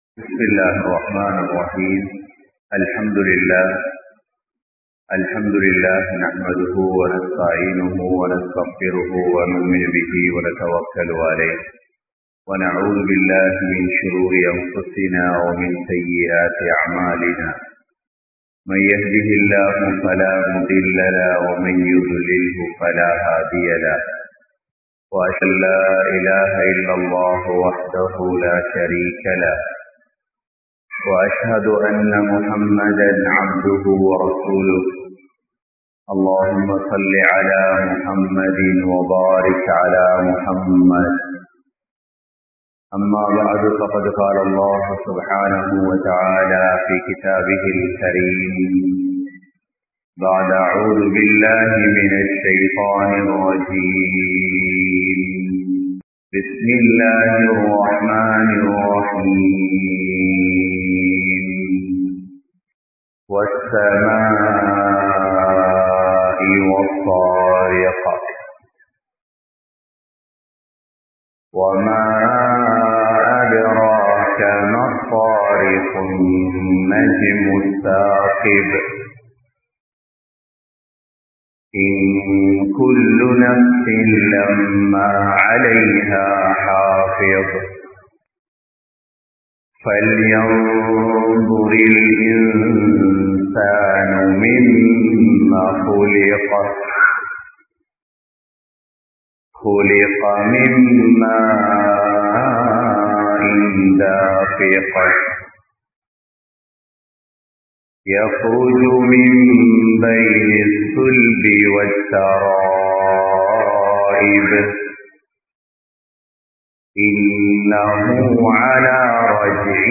Yaarum Thappa Mudiyaatha Maranam (யாரும் தப்பமுடியாத மரணம்) | Audio Bayans | All Ceylon Muslim Youth Community | Addalaichenai